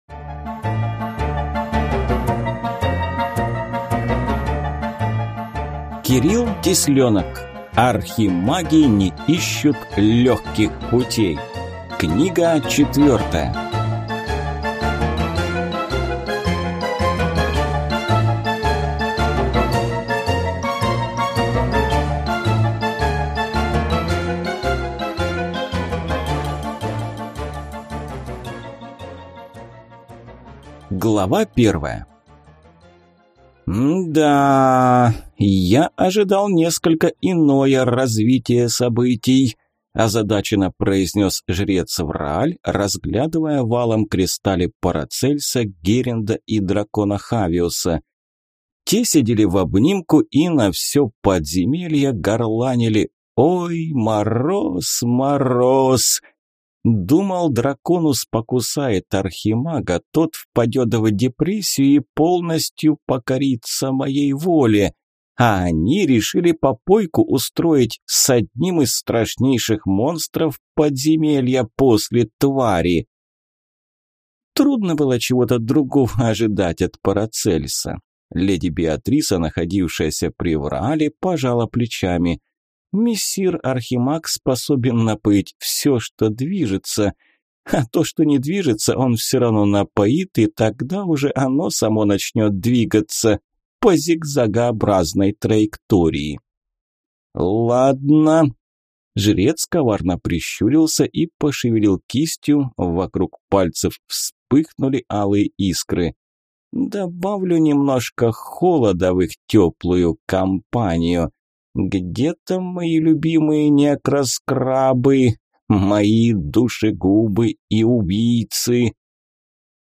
Аудиокнига Архимаги не ищут легких путей. Книга 4 | Библиотека аудиокниг